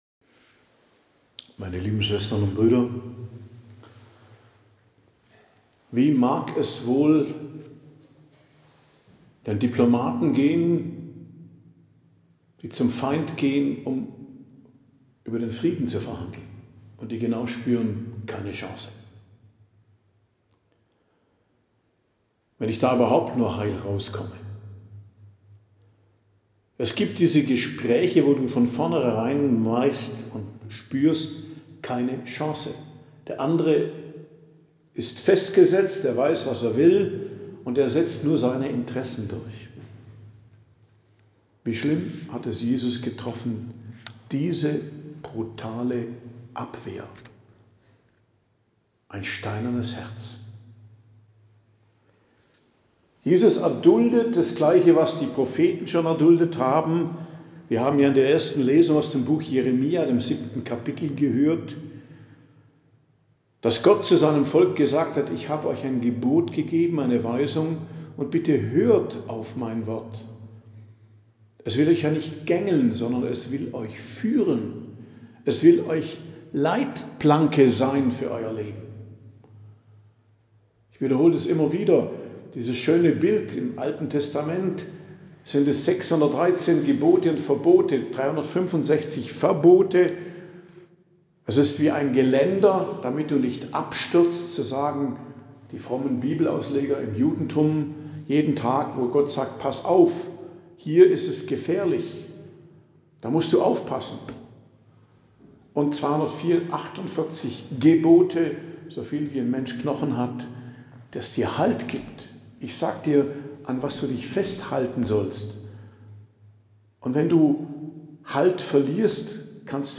Predigt am Donnerstag der 3. Woche der Fastenzeit, 27.03.2025 ~ Geistliches Zentrum Kloster Heiligkreuztal Podcast